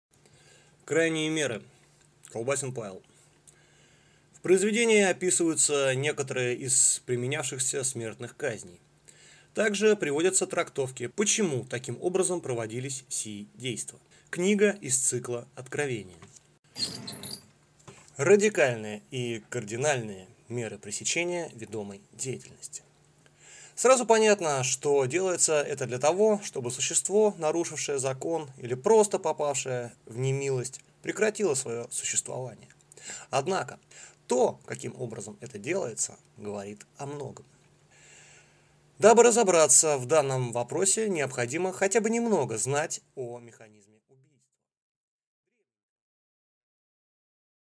Аудиокнига Крайние меры | Библиотека аудиокниг